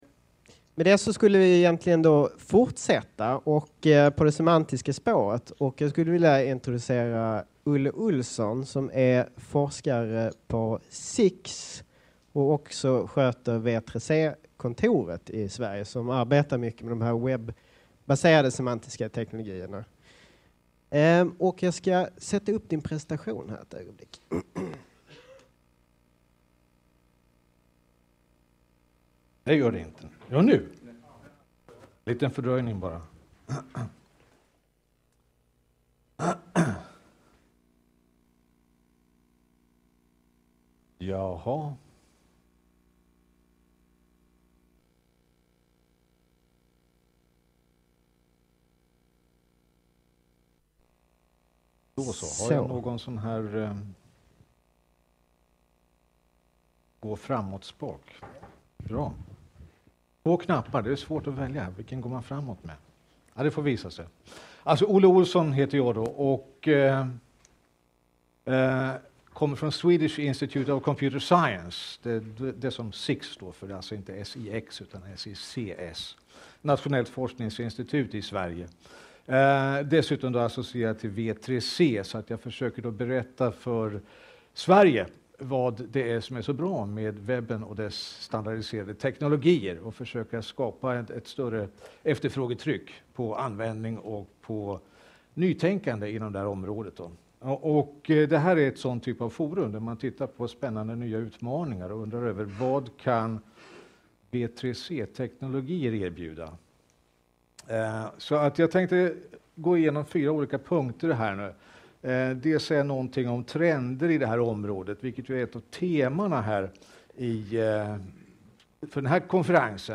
ITARC 2010 är över där c:a 150 arkitekter möttes för att diskutera heta IT arkitekturfrågor.